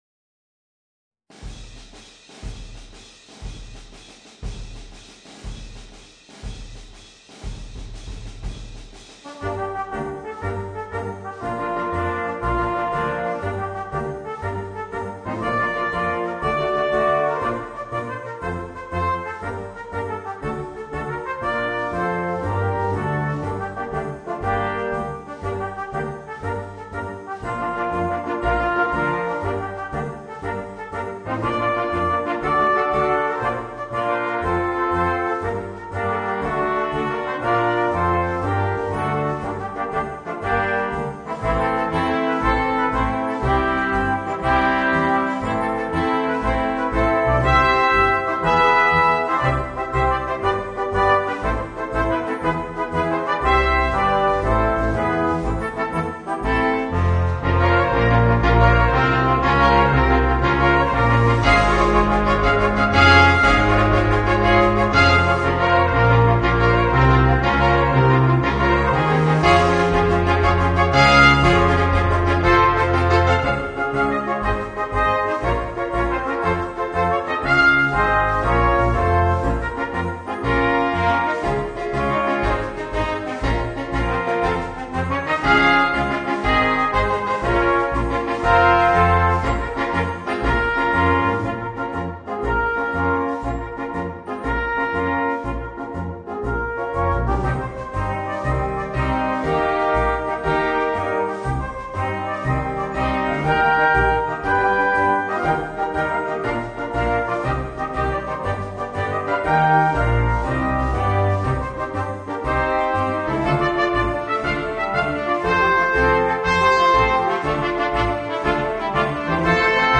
Voicing: 5 - Part Ensemble and Piano / Keyboard